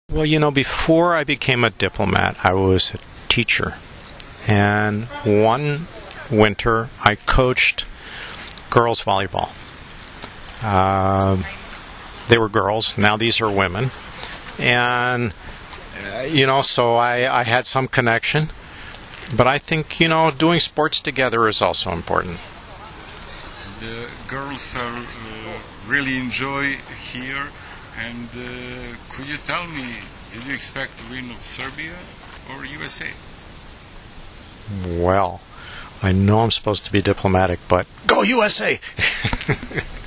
Njegova ekselencija Majkl Kirbi, ambasador SAD u Srbiji, priredio je večeras u svojoj rezidenciji na Dedinju, u Beogradu, prijem za kompletne reprezentacije SAD i Srbije, koje će narednog vikenda igrati na turniru F grupe II vikenda XXI Gran Prija 2013.
IZJAVA NJEGOVE EKSELENCIJE MAJKLA KIRBIJA, AMBASADORA SAD U SRBIJI